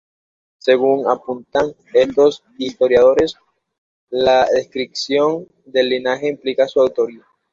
/liˈnaxe/